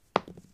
diceThrow1.ogg